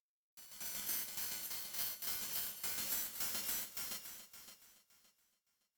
ホラー系効果音
フリー音源　ホラー系BGM